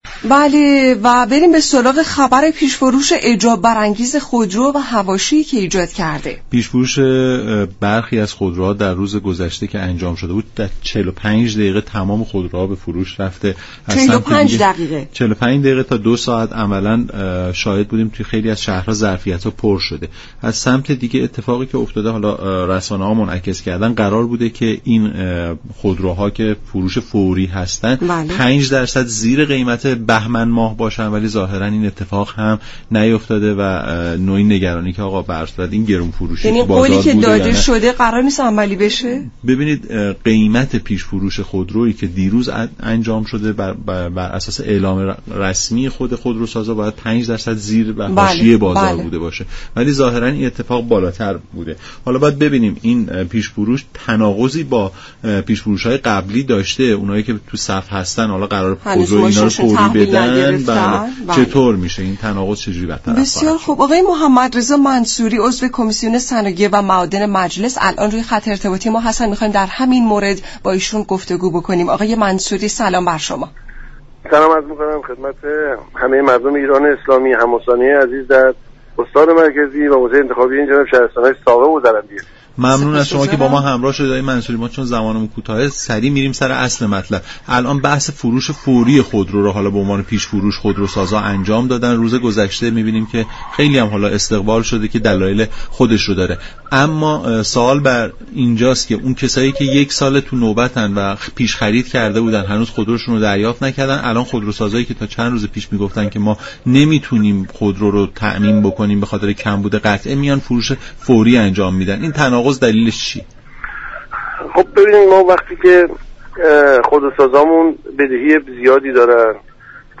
محمدرضا منصوری عضو كمیسیون صنایع و معادن مجلس شورای اسلامی در گفت و گو با برنامه نمودار رادیو ایران به بحث فروش فوری خودرو پرداخت و در این باره گفت: پیش فروش ویژه خودرو كه طرحی غیر عادی است، چشم انداز روشنی ندارد.
در ادامه این گفت و گو را باهم بشنویم.